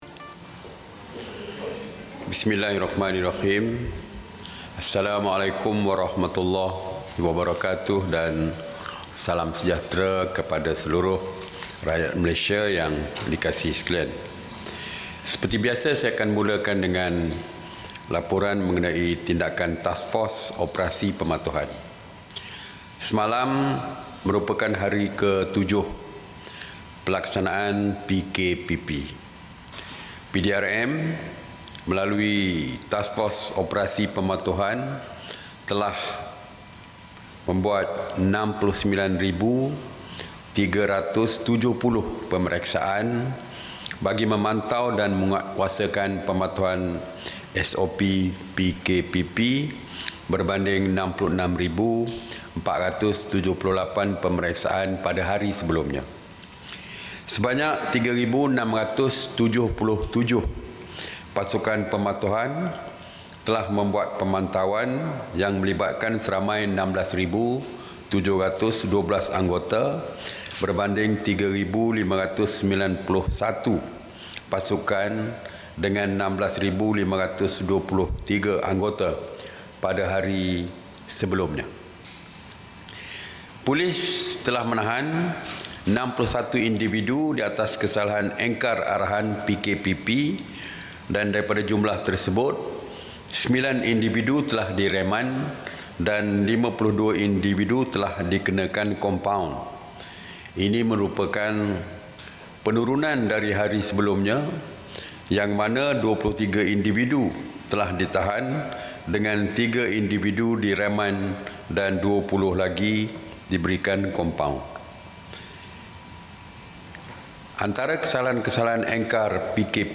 [SIDANG MEDIA] Perintah Kawalan Pergerakan Pemulihan (PKPP)
Ikuti sidang media oleh Menteri Kanan Keselamatan, Datuk Seri Ismail Sabri Yaakob bekaitan Perintah Kawalan Pergerakan Pemulihan, PKPP.